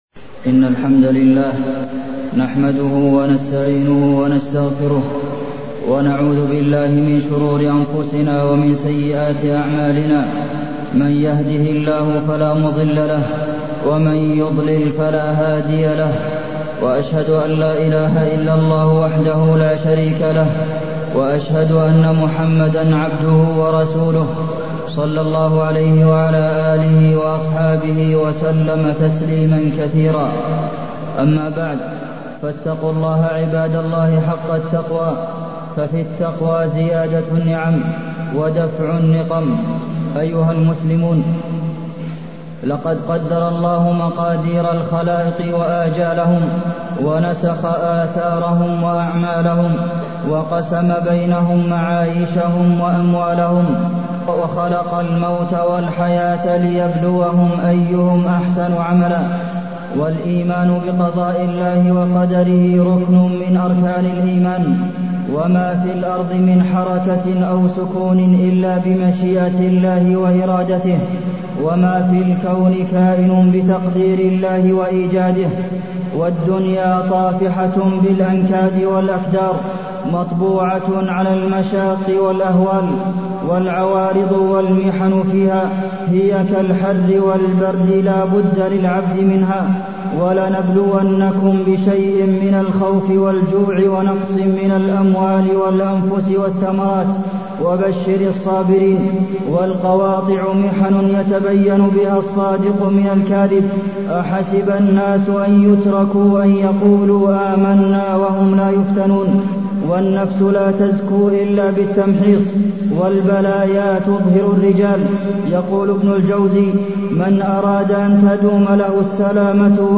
تاريخ النشر ٥ محرم ١٤٢٢ هـ المكان: المسجد النبوي الشيخ: فضيلة الشيخ د. عبدالمحسن بن محمد القاسم فضيلة الشيخ د. عبدالمحسن بن محمد القاسم الابتلاء والتمسك بالعروة الوثقى